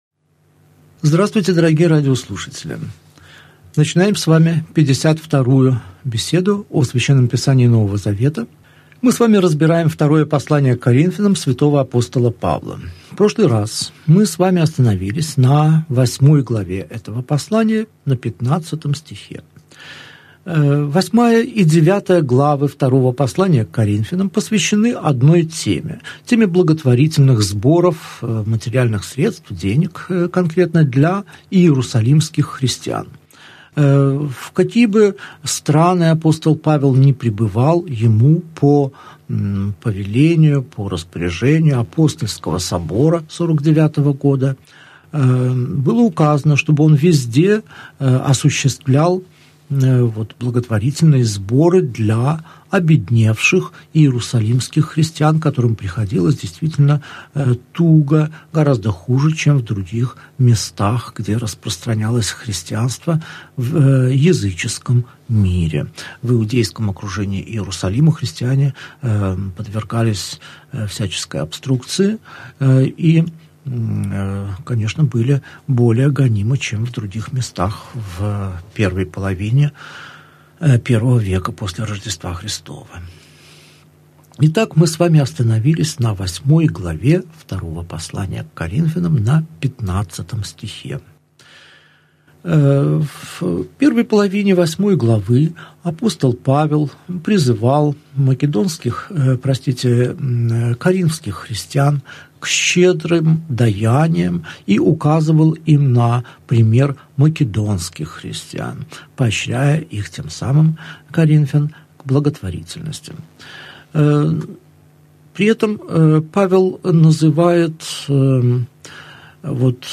Аудиокнига Беседа 52. Второе послание к Коринфянам. Глава 8, стих 16 – глава 6, стих 15 | Библиотека аудиокниг